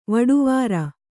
♪ vaḍuvāra